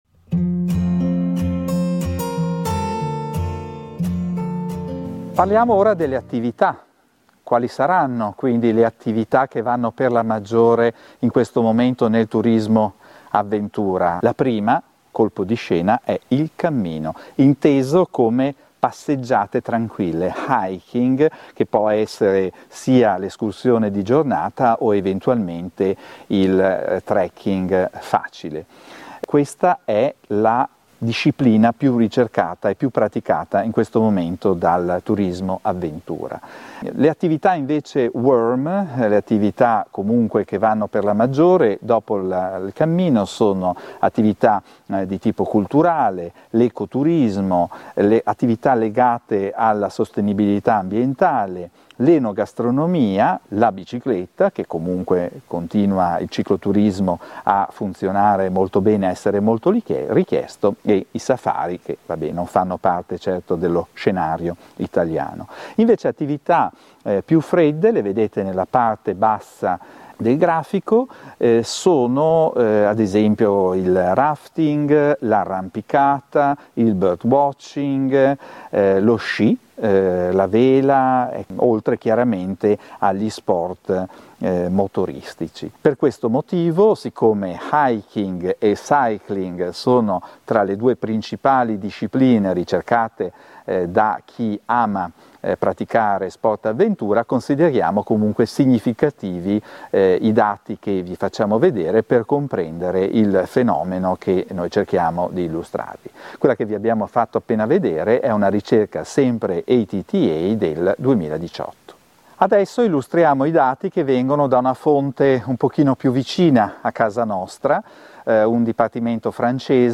Scarica l'audio (mp3) Scarica le slide (pdf) In questa lezione vi spiego quali sono le attività più praticate dai viaggiatori internazionali: il cammino si rivela l'attività di punta, e anche la bicicletta è una delle discipline più importanti.